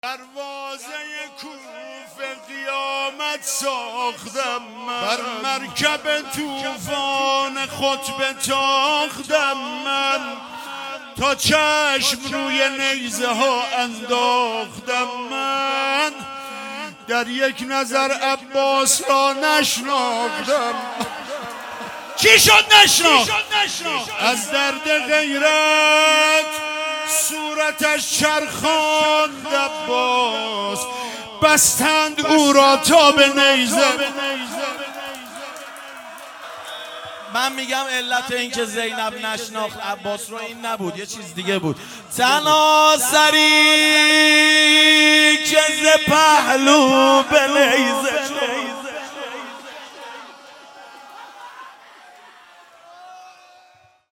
مداحی
ایام زیارت مخصوص امام رضا(ع) | مشهد الرضا تیر ماه 1400